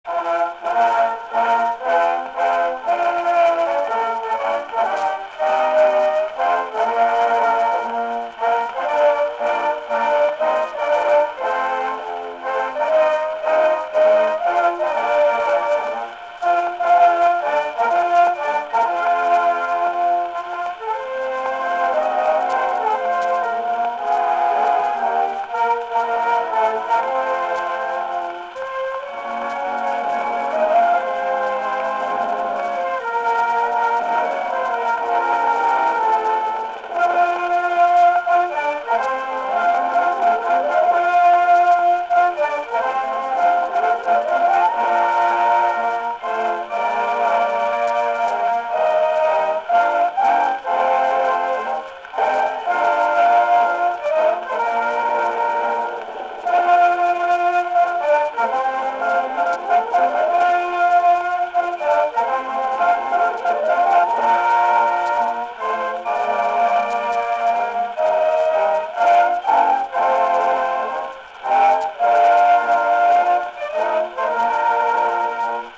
«Марсельеза» (оркестр Республиканской гвардии, Париж, 1908 год).